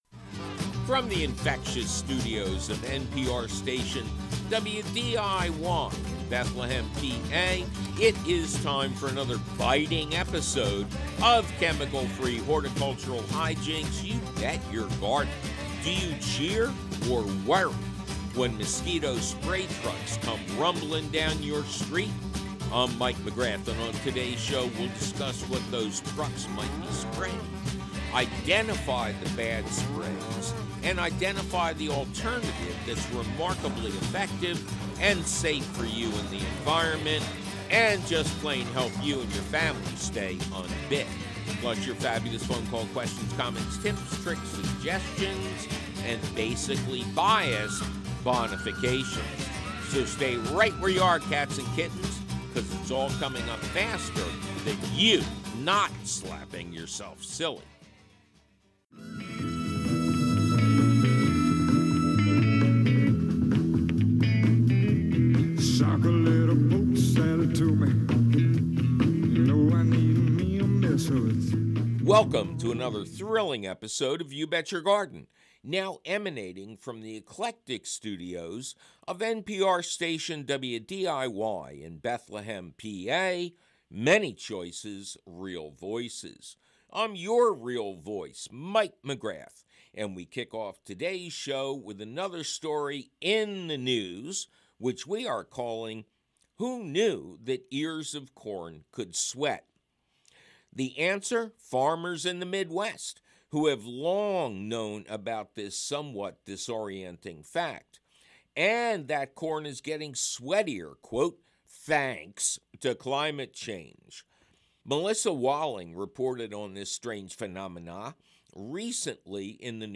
Plus your intelligently infectious phone calls!